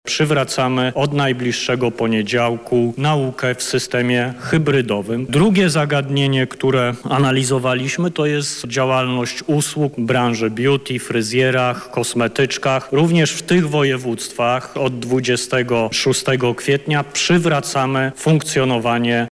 • mówi Niedzielski.